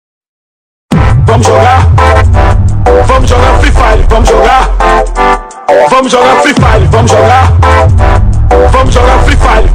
vamo jogar free fire Meme Sound Effect